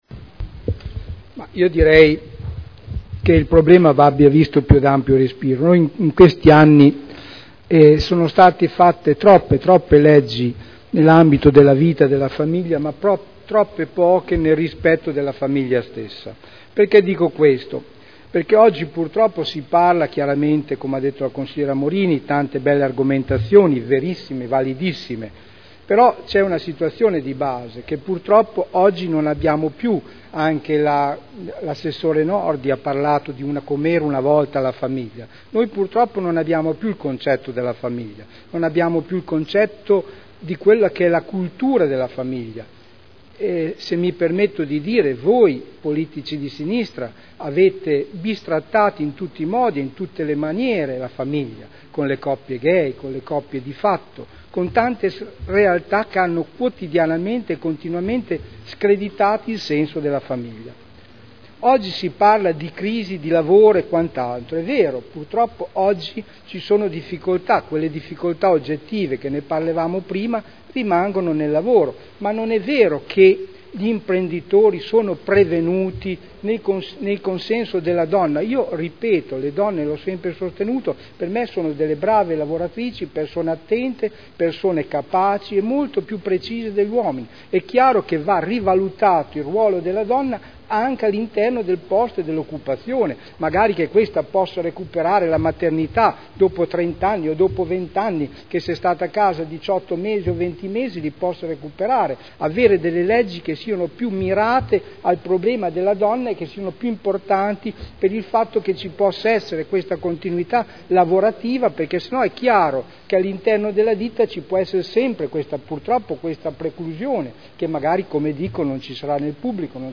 Sergio Celloni — Sito Audio Consiglio Comunale
Seduta del 18/04/2011.